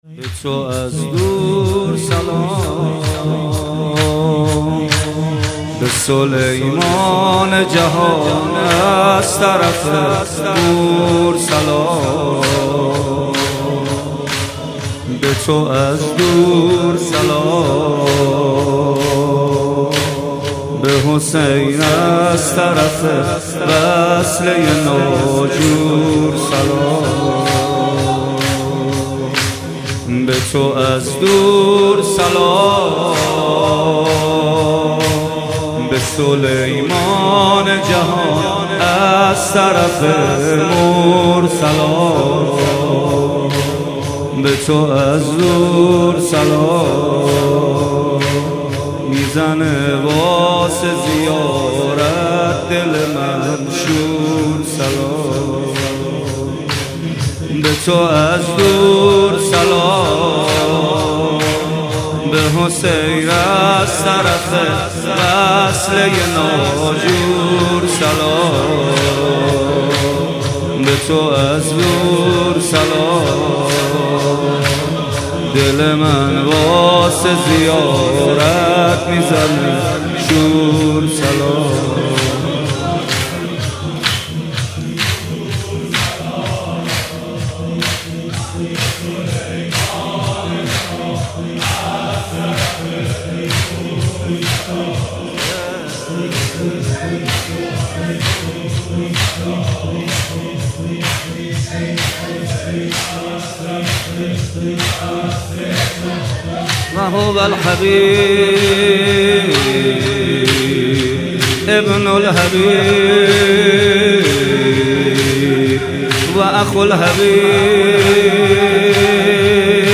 متن نوحه